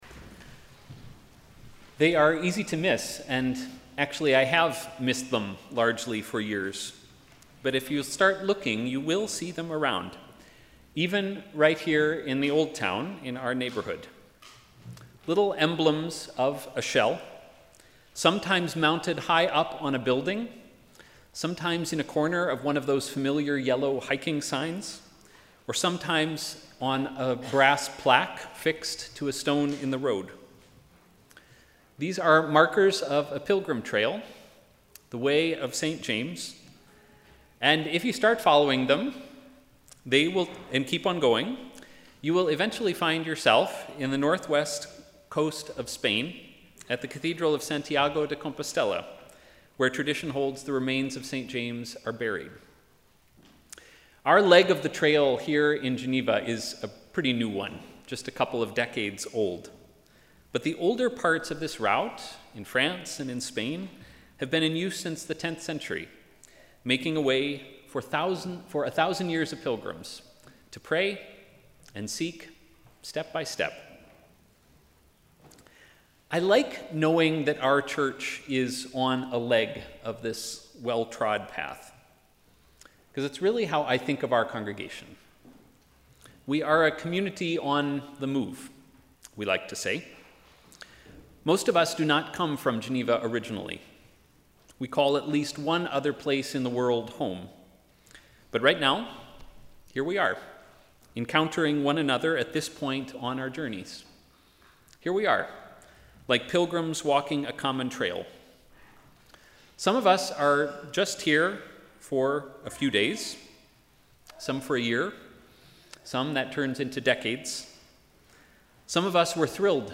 Sermon: ‘A blessing on the way’